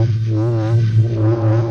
Index of /musicradar/rhythmic-inspiration-samples/140bpm